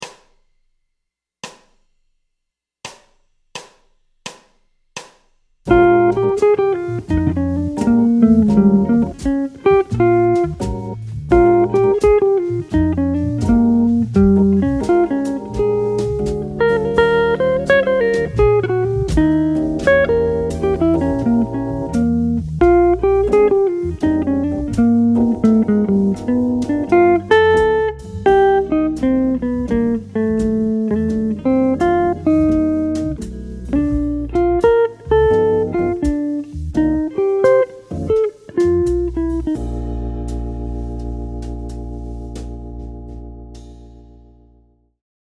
Rajout de la Tierce Majeure (note D) et de la Sixte (note G) à un fragment de gamme pentatonique mineure de Bb.
Mélange de gamme et d’arpèges qui va très bien sur le I7 du Blues :
Exemple d’emploi sur un Blues en Bb
Blues-Lick-6-1.mp3